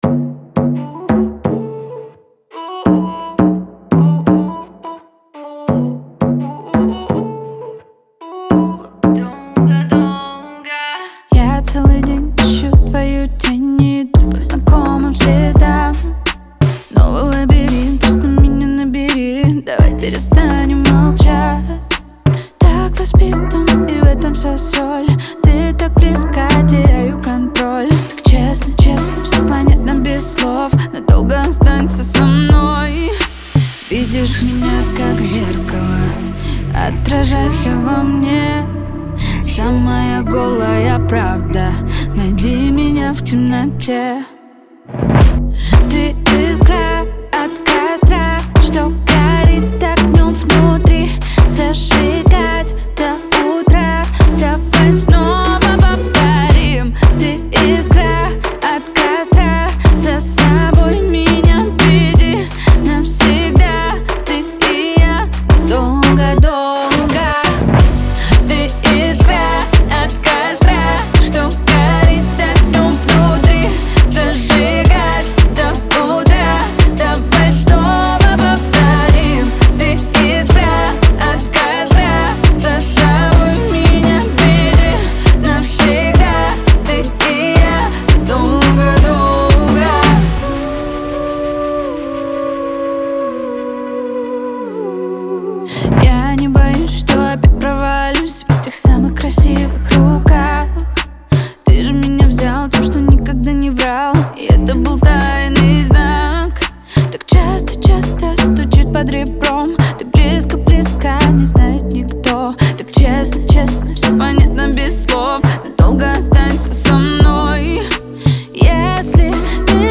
это энергичный трек в жанре поп-рок